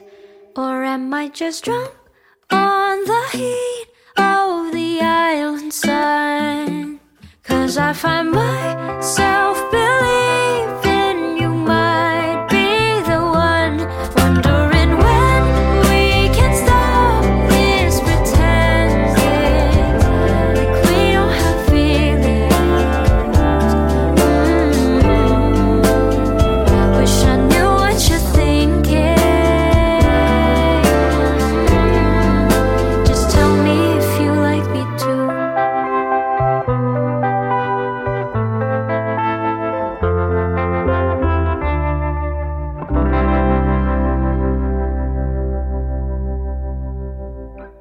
penyanyi sopran yang terlatih secara klasik